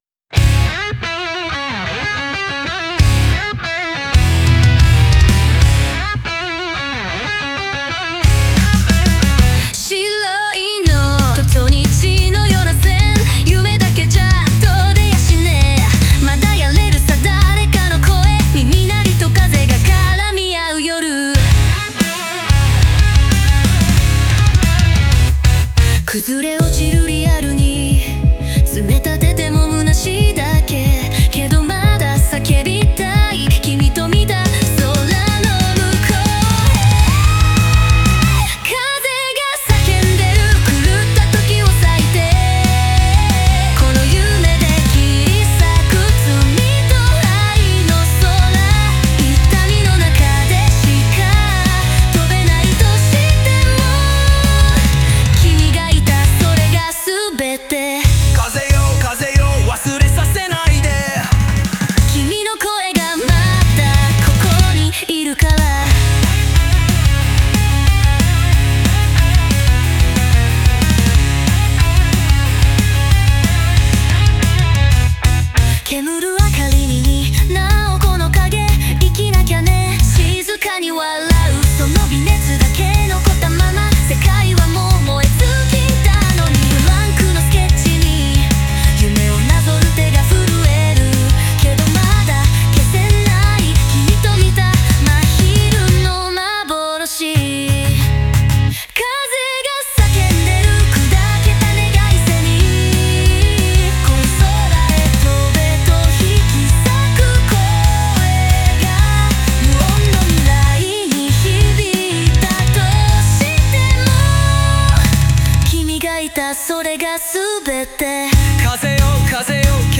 オリジナル曲♪
激しいビートの中に、静かな記憶が重なることで、人生の儚さと美しさが交錯する構成になっています。